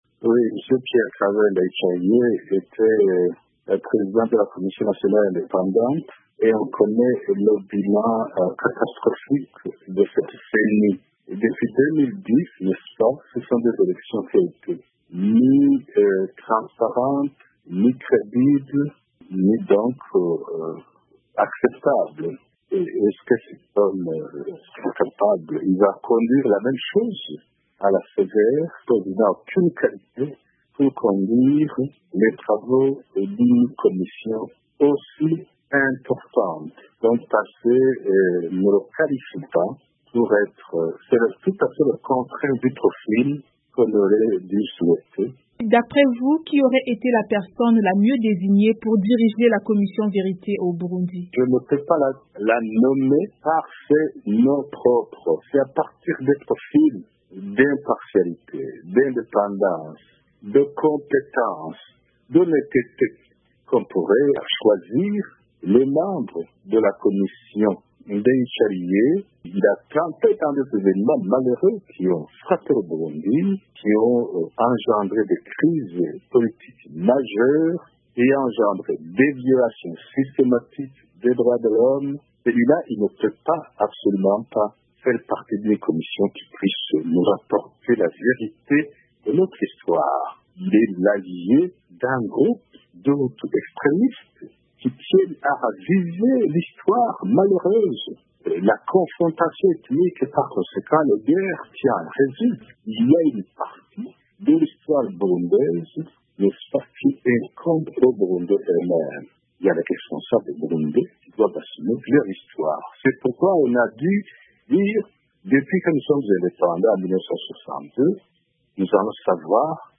Ce qui "n’est pas le cas de Pierre-Claver Ndayicariye ou de la plupart des membres qui sont les hommes de main du président Pierre Nkurunziza", déclare ce membre de l’Union pour le progrès national dans une interview accordée à VOA vendredi 23 novembre 2018.